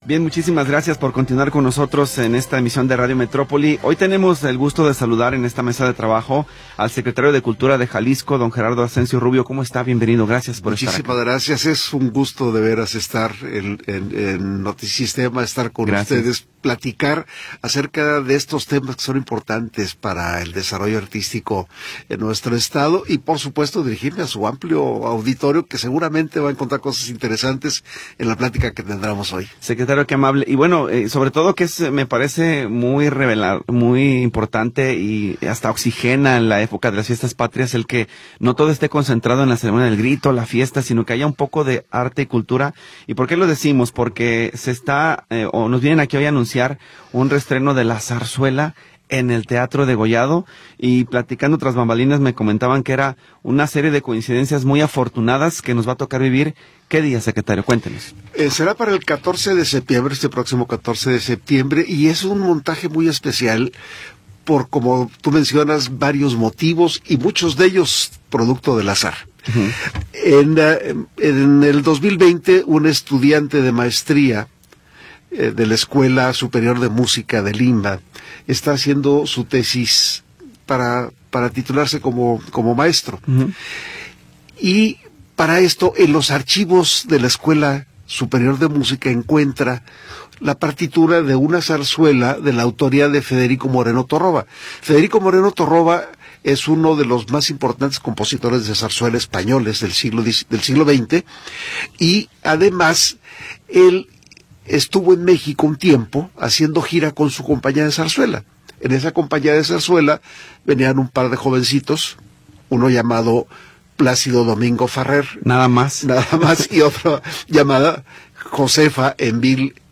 Entrevista con Gerardo Ascencio Rubio